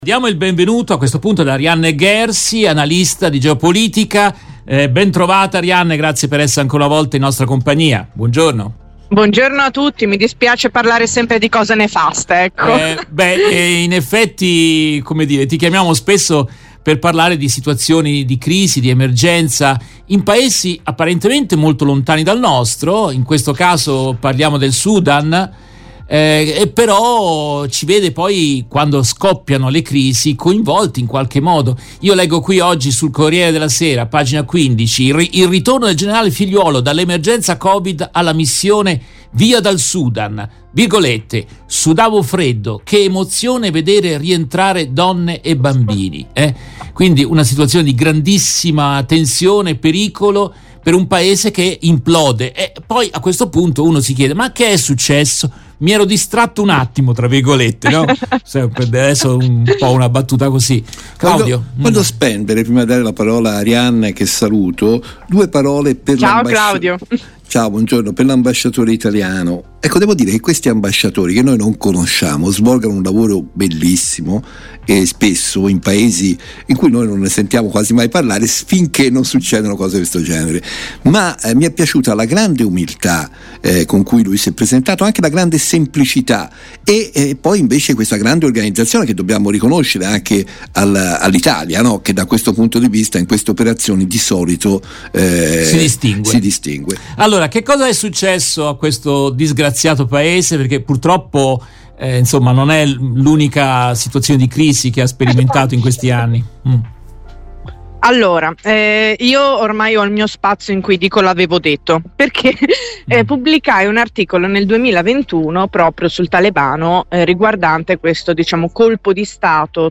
Nel corso della diretta RVS del 26 aprile 2023